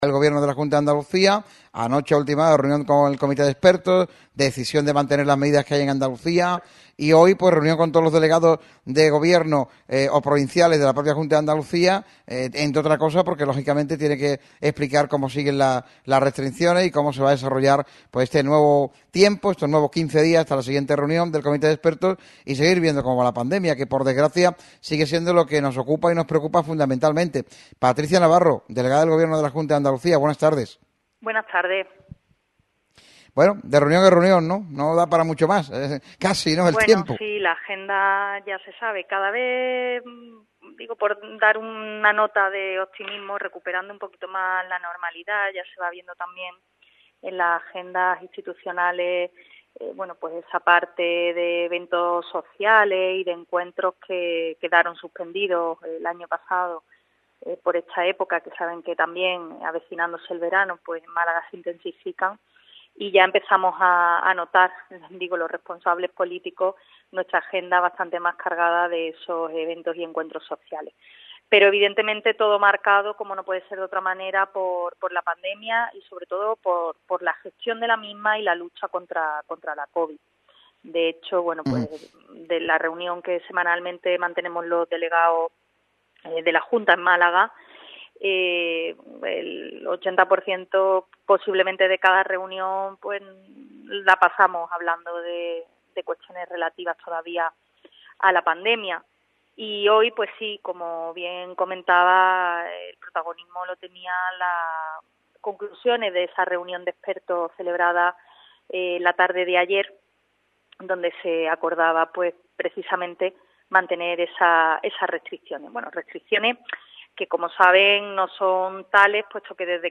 Patricia Navarro, delegada del Gobierno de la Junta de Andalucía, ha pasado por los micrófonos de Radio MARCA Málaga para hacer un análisis de la situación pandémica que atraviesa la provincia.